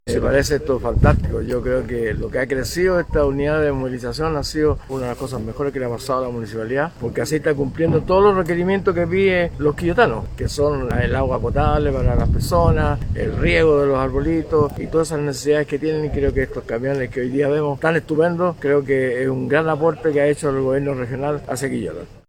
04-CONCEJAL-REBOLAR-Un-gran-aporte.mp3